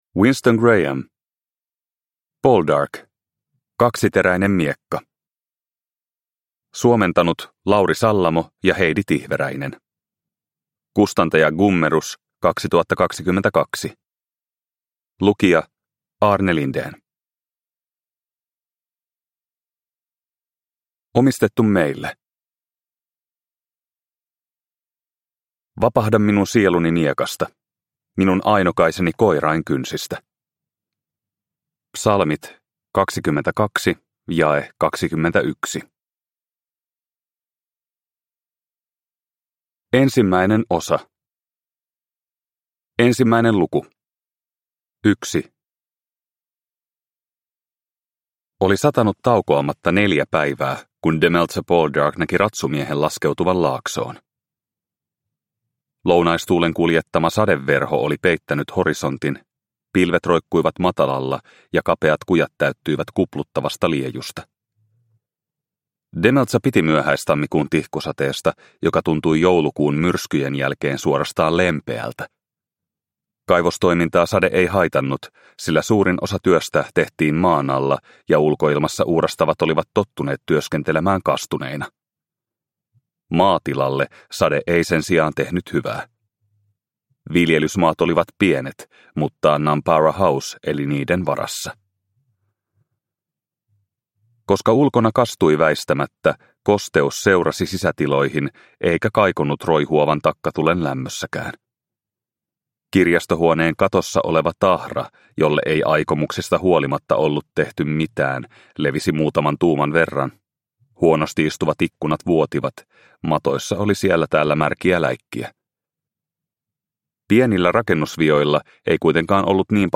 Poldark - Kaksiteräinen miekka (ljudbok) av Winston Graham